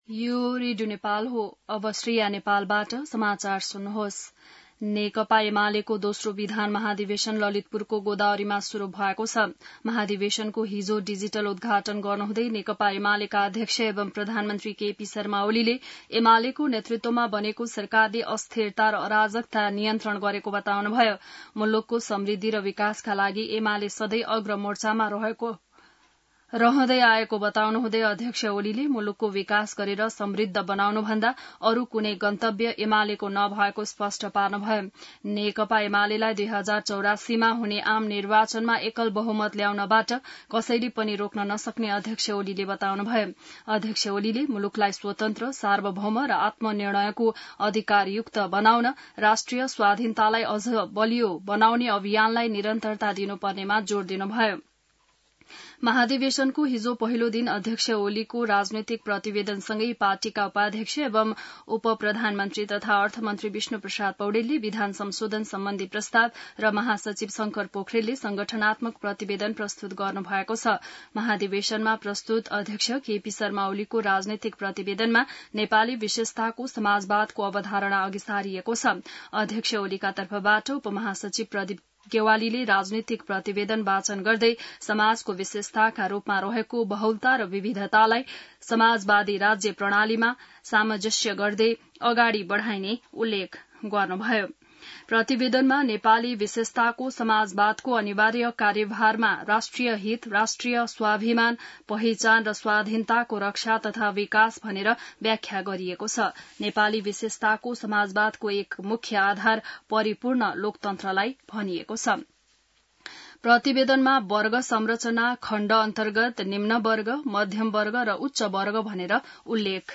बिहान ६ बजेको नेपाली समाचार : २१ भदौ , २०८२